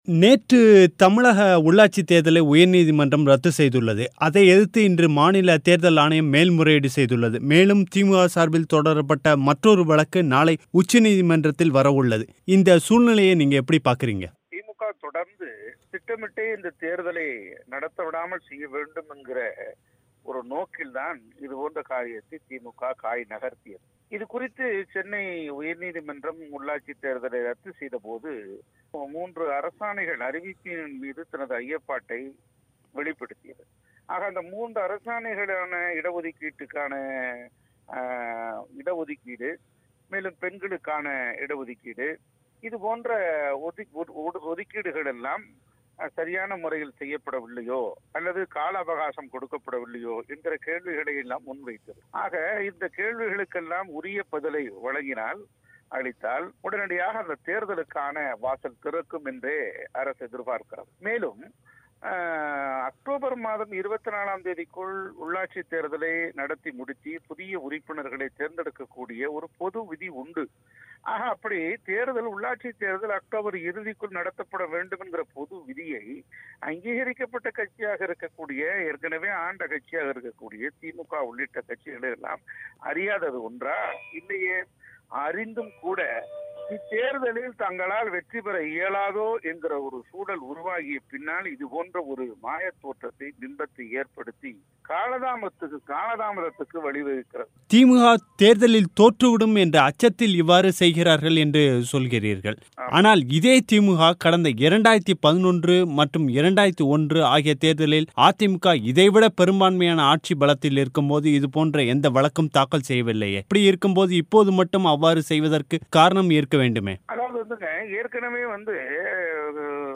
இது குறித்தும், திமுக உள்ளிட்ட எதிர்க்கட்சிகள் கூறும் குற்றச்சாட்டுகள் குறித்த பதில்களை அதிமுகவின் செய்தித்தொடர்பாளர் வைகைச்செல்வன் நம்முடன் பகிர்ந்து கொண்டார்